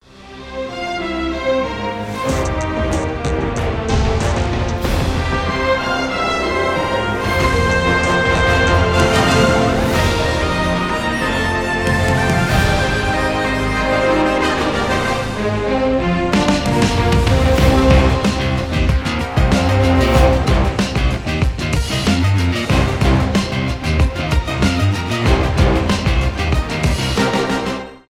без слов
инструментальные